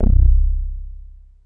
Super_BassStation_04(C1).wav